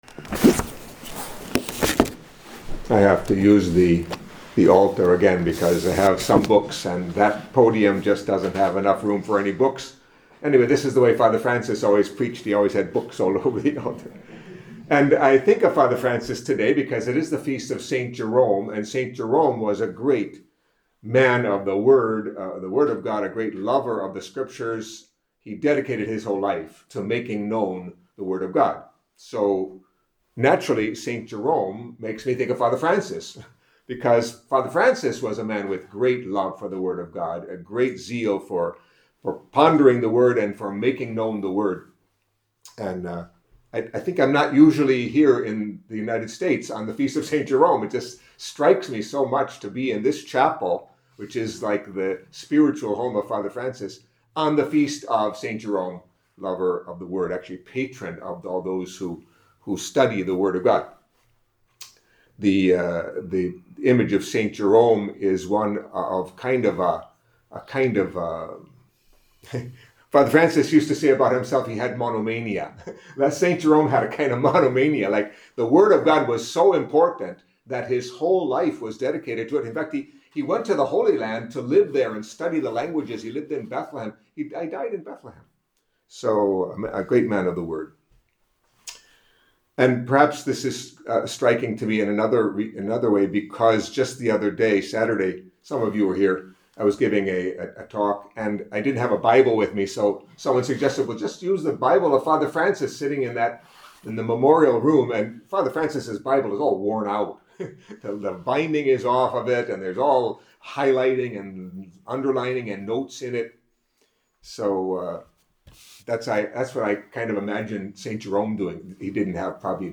Catholic Mass homily for Tuesday of the Twenty-Sixth Week in Ordinary Time